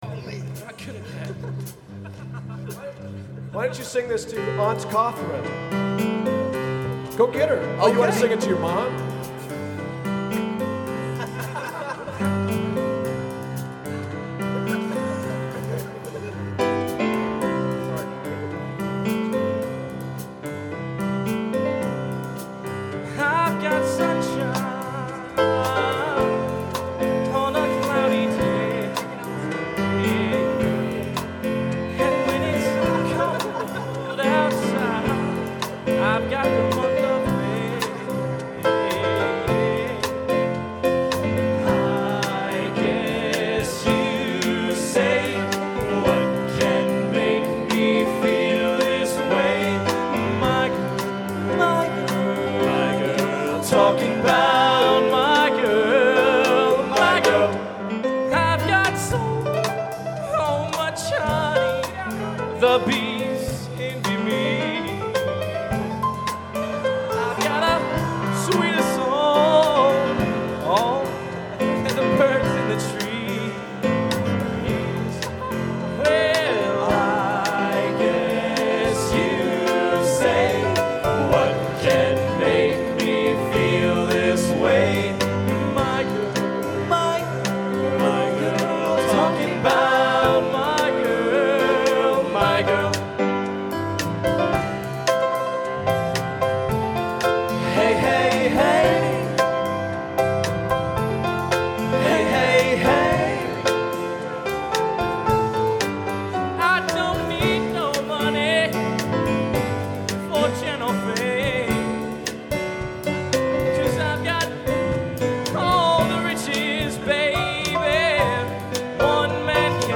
Genre: Spiritual | Type: